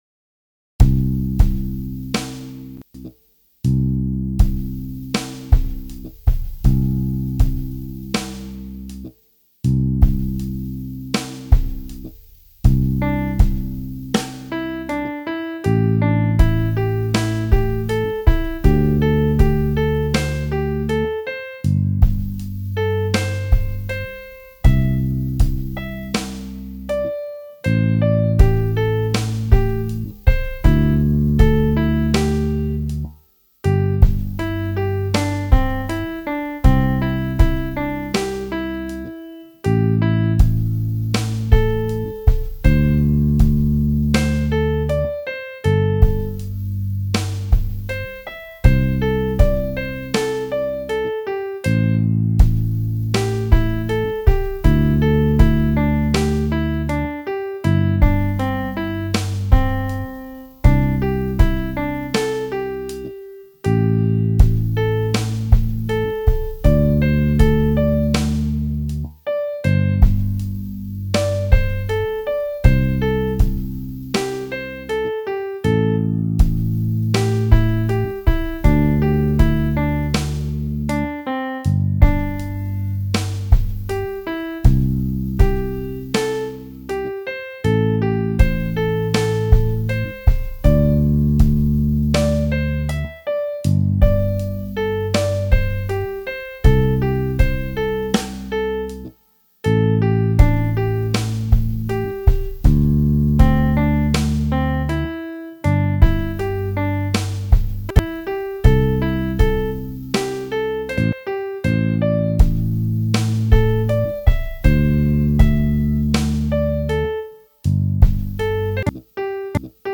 C Major Pentatonic Slow Tempo